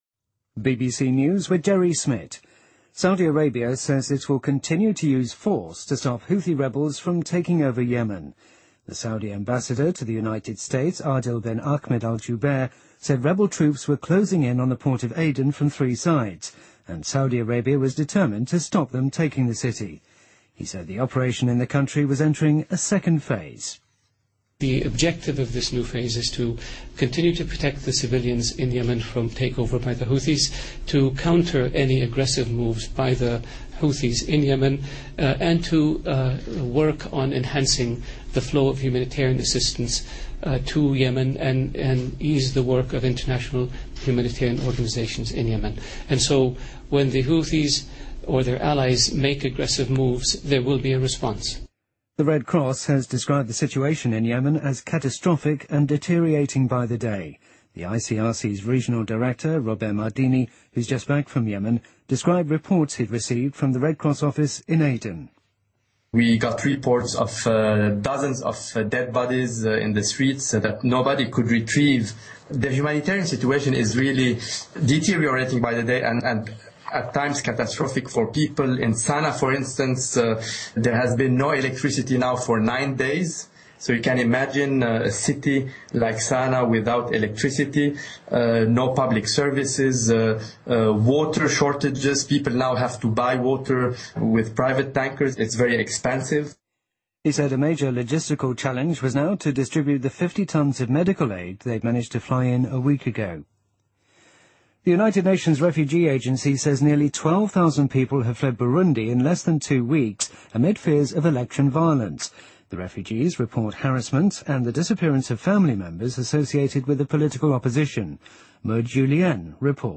日期:2015-04-23来源:BBC新闻听力 编辑:给力英语BBC频道